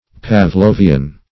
pavlovian - definition of pavlovian - synonyms, pronunciation, spelling from Free Dictionary